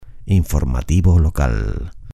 Locución gratuita para programas de televisión.
informativo_locales_locucion_television_locutortv.mp3